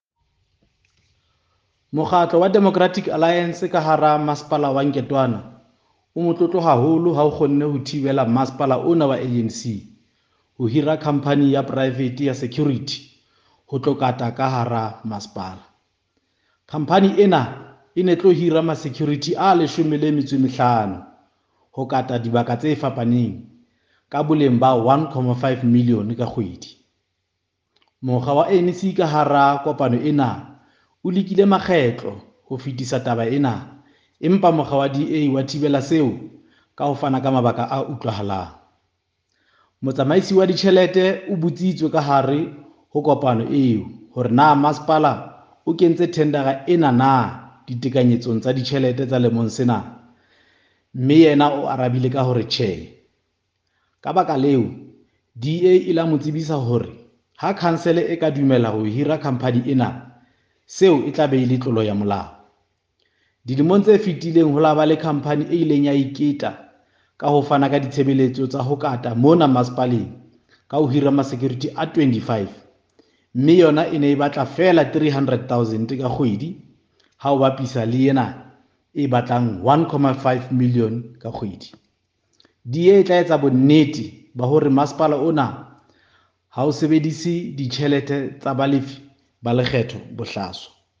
Sotho soundbites by Cllr Diphapang Mofokeng.